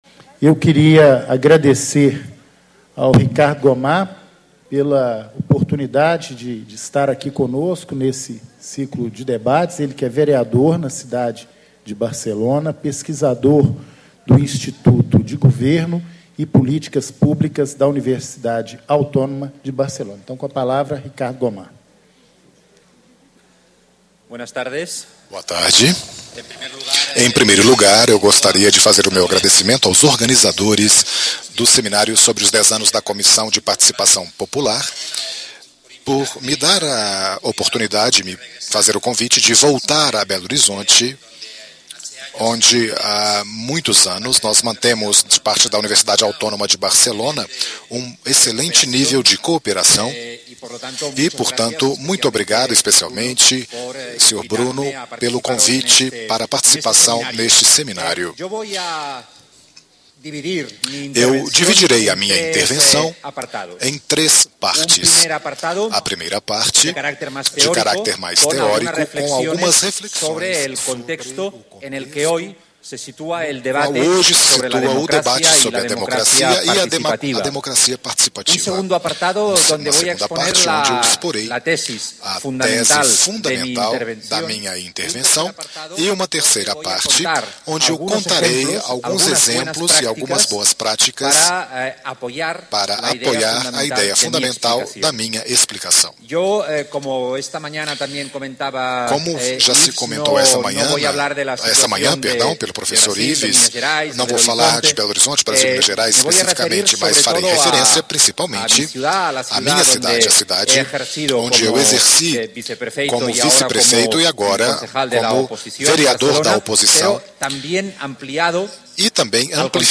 Palestra - Ricard Gomà - Institut de Govern i Polítiques Publiques (IGOP) - Universitat Autònoma de Barcelona - Painel: As Boas Práticas de Participação Popular no Poder Legislativo (com tradução simultânea do Espanhol para o Português)